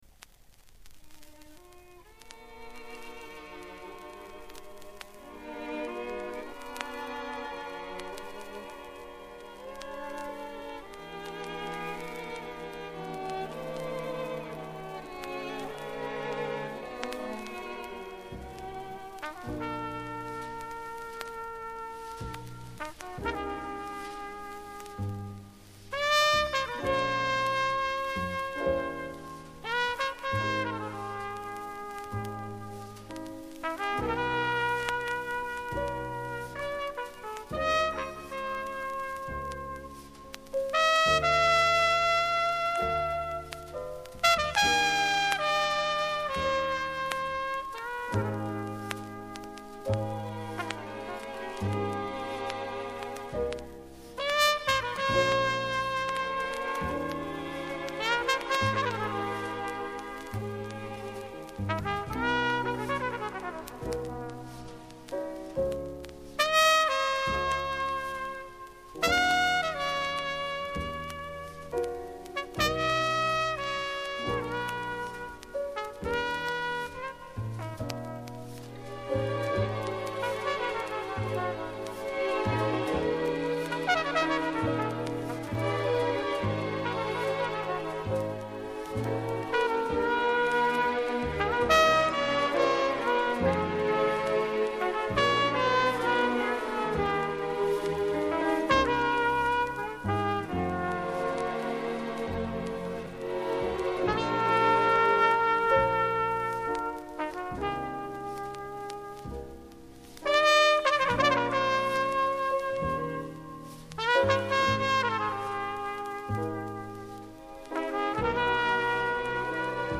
Еше один джазовый музыкант-трубач из Чехословакии